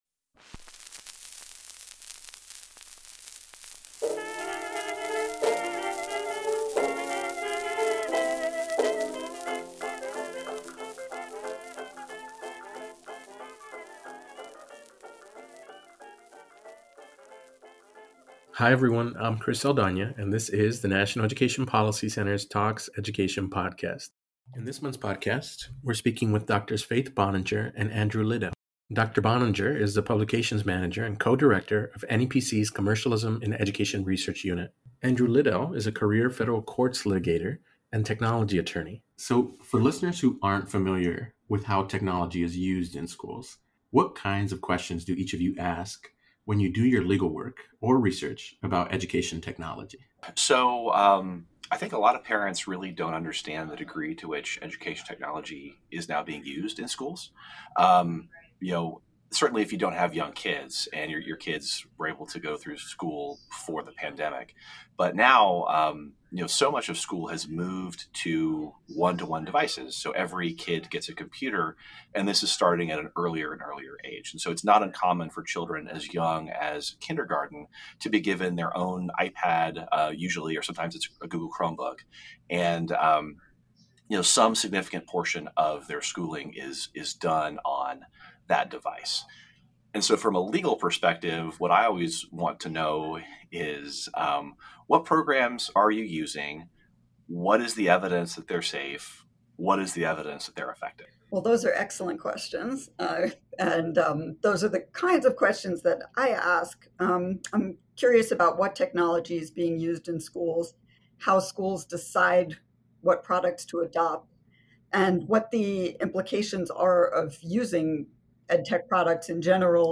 NEPC Talks Education: An Interview